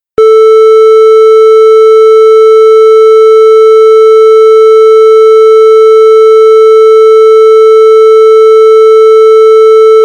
A0 440Hz.mp3
a0-440hz.mp3